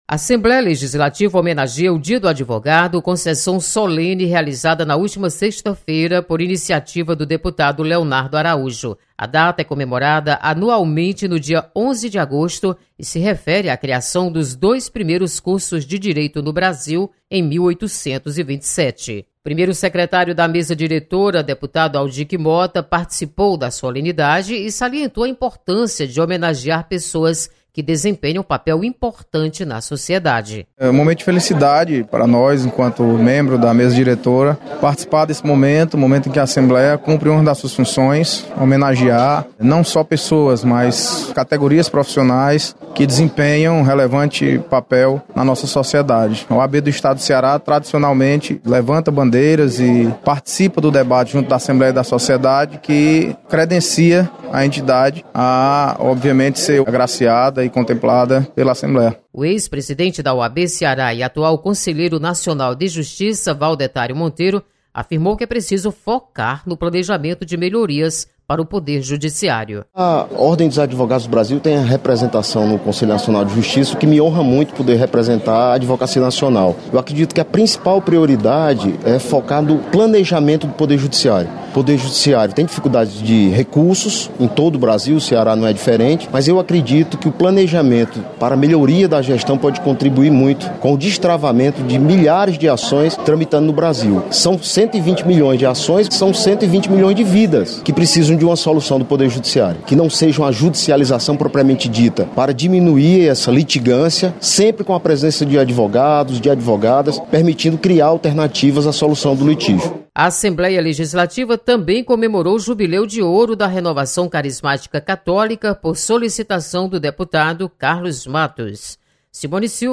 Assembleia presta homenagem ao Dia do Advogado. Repórter